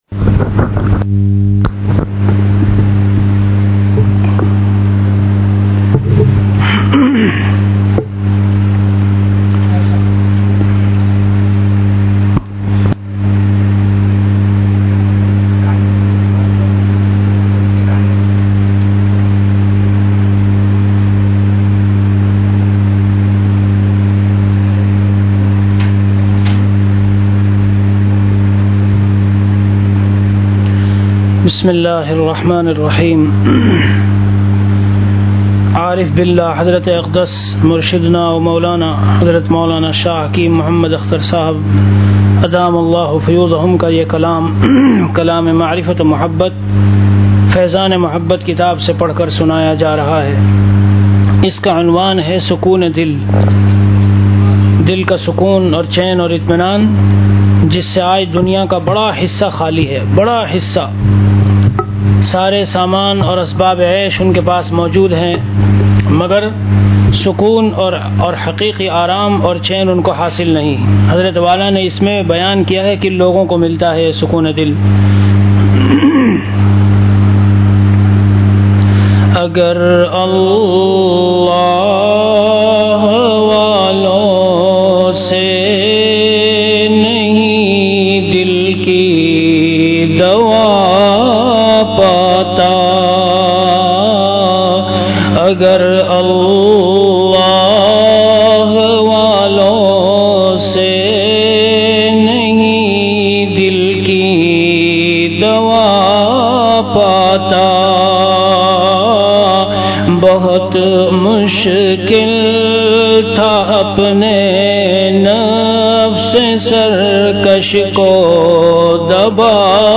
An Islamic audio bayan
Delivered at Khanqah Imdadia Ashrafia.